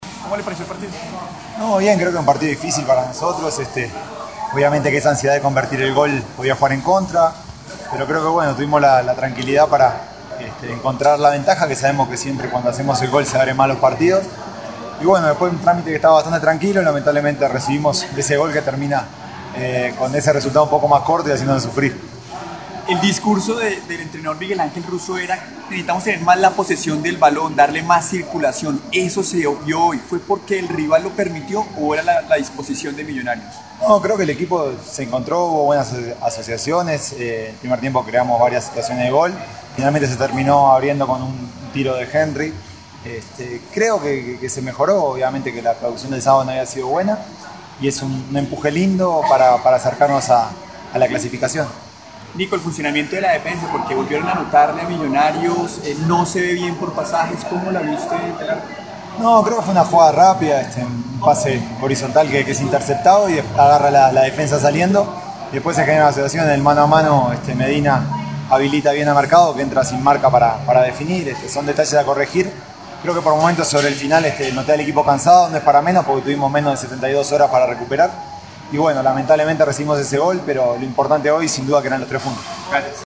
El portero azul habló con los medios de comunicación luego del partido y entregó su balance de lo sucedido en la cancha en la victoria sobre Cortuluá.